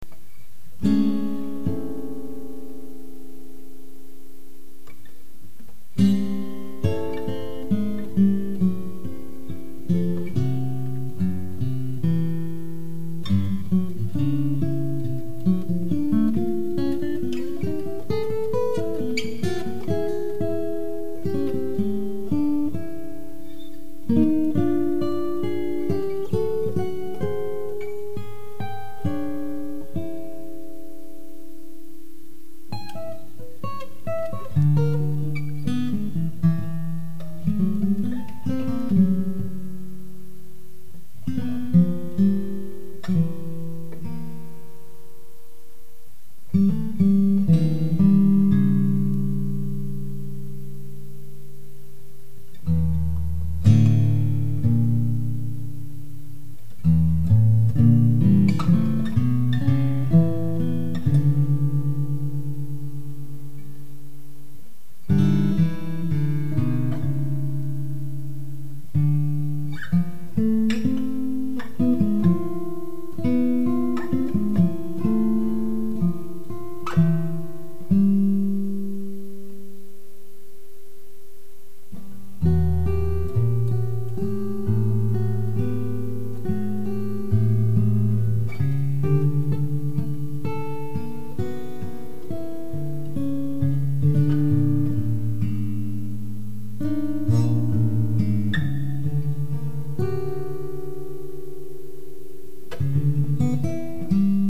Duet for Two Guitars